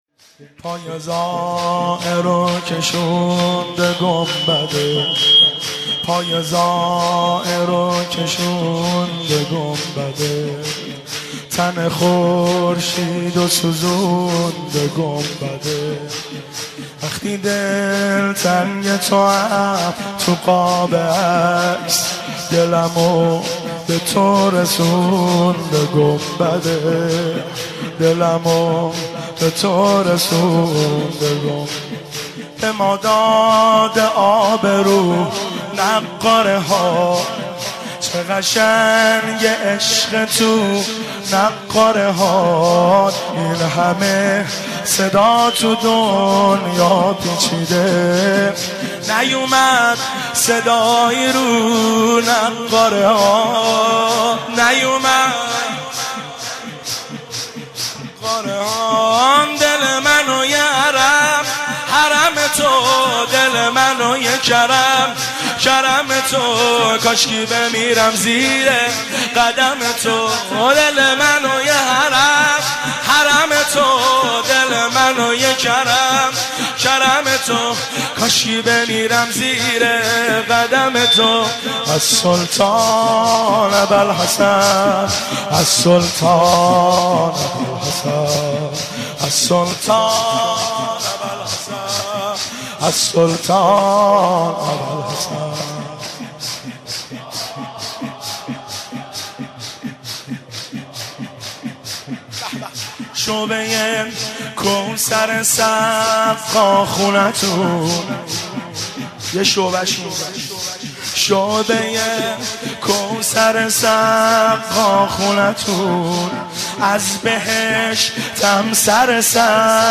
مداحان